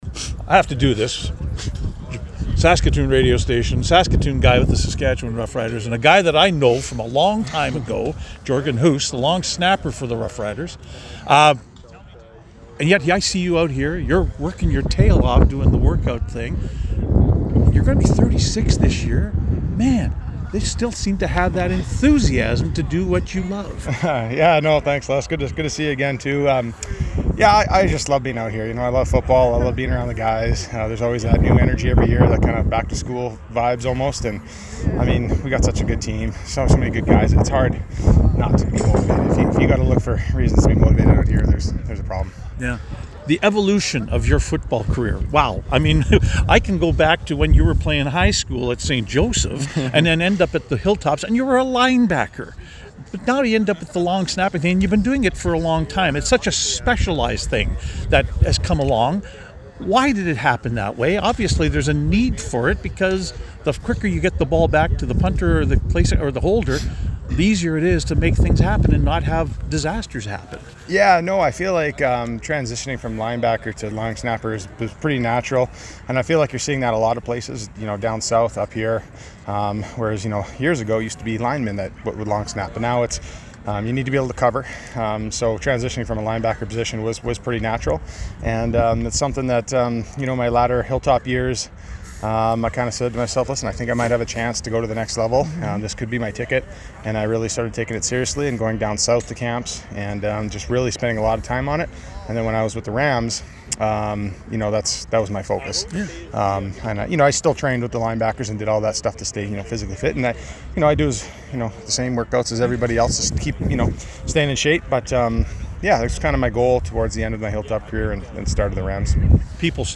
Another cool, breezy morning at Griffiths Stadium for the sixth day of main training camp for the Saskatchewan Roughriders.